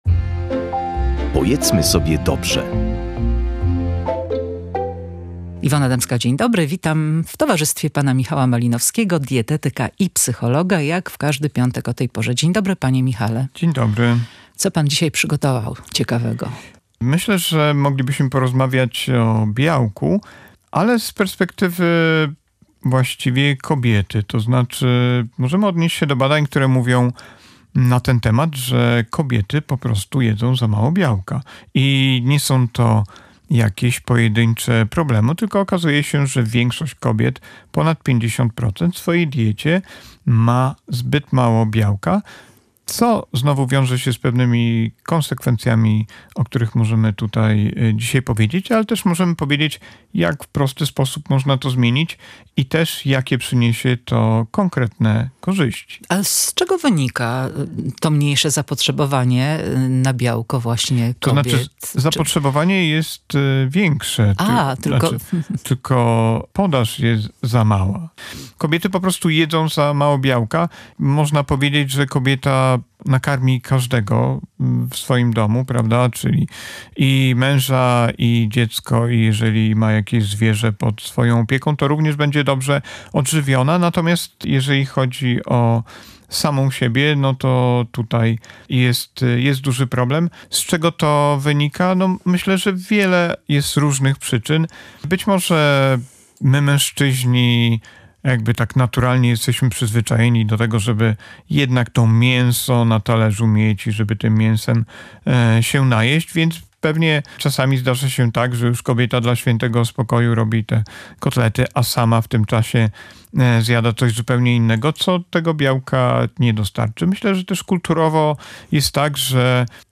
Dlaczego białko jest tak istotne w diecie, zwłaszcza u kobiet? Posłuchaj rozmowy